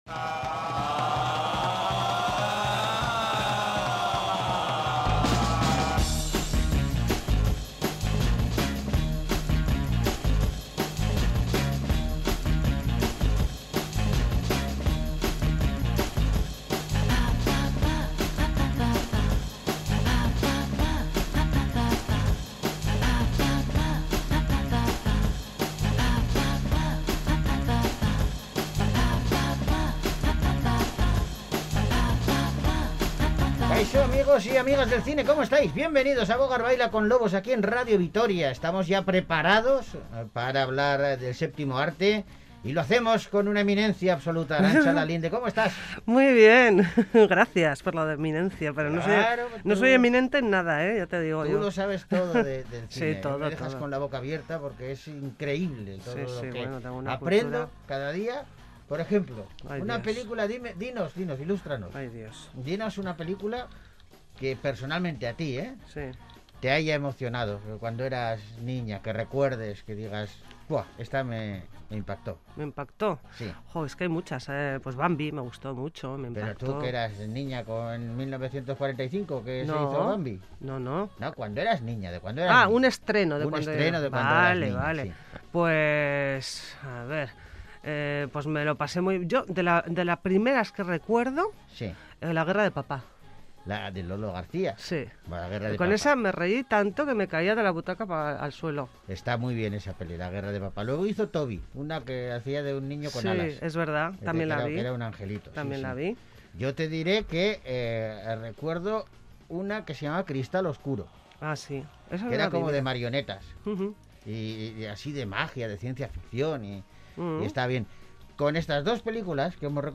Toda la actualidad cinematográfica con los estrenos de la semana, ¡Entérate qué se está rodando! ¡Escucha las entrevistas a los actores o directores de cine y c